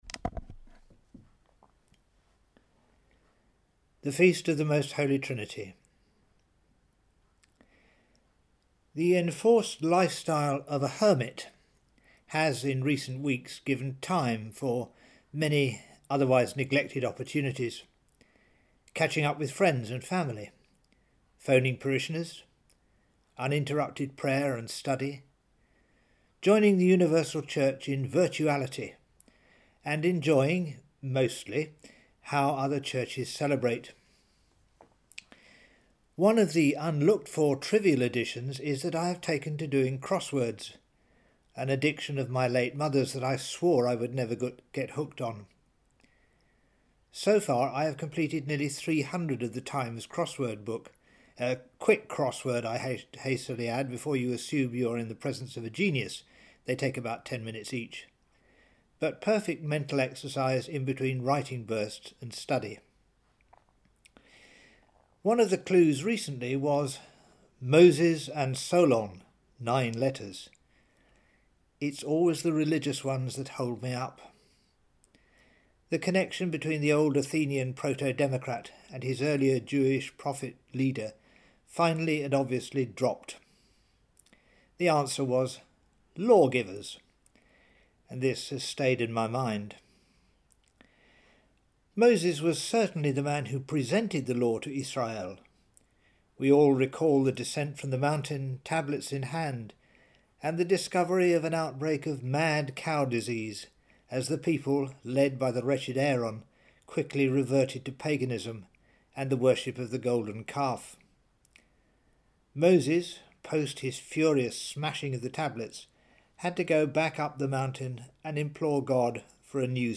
A Catholic homily